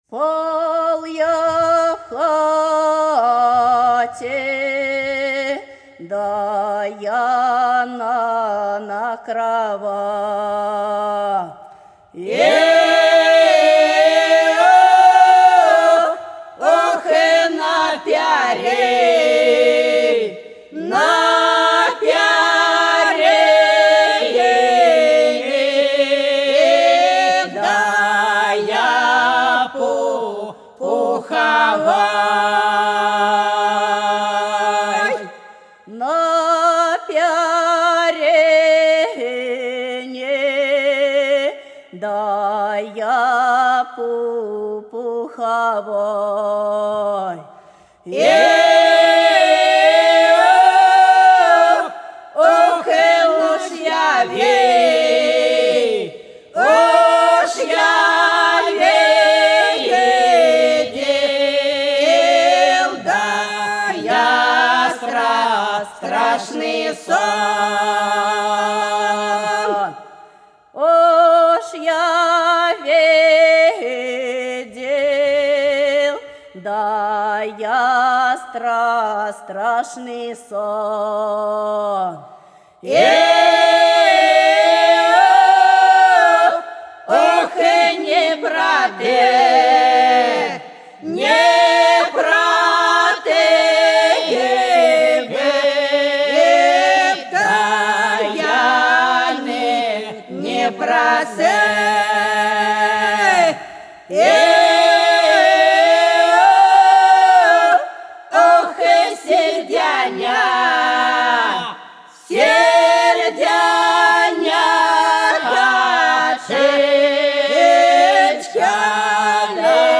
За речкою диво (Бутурлиновский и Павловский районы) 012. Спал я в хате на кровати — протяжная.
Запись сделана в студии в 2003 году.